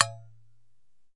描述：用剪刀击打不锈钢保温瓶]： ＆gt;
标签： 真空瓶 不锈钢钢制 钢保温瓶 进行明亮的高 金属 不锈钢 命中 保温瓶
声道立体声